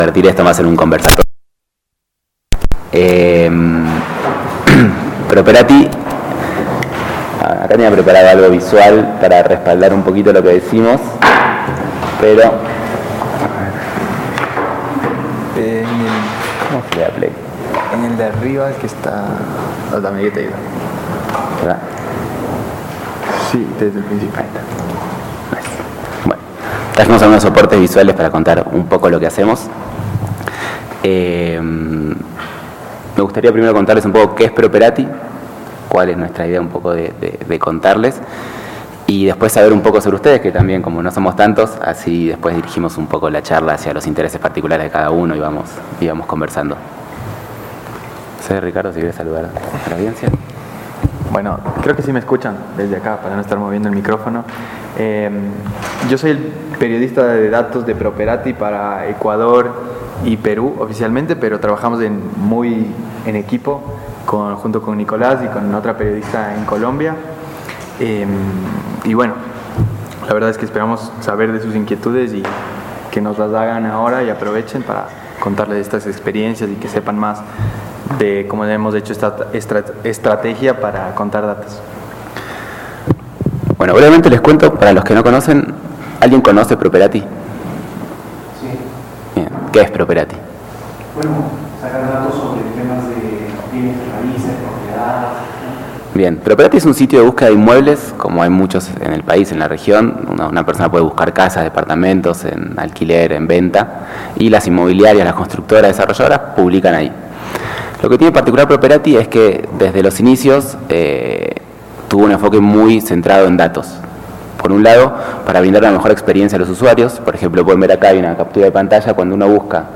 (Expositor)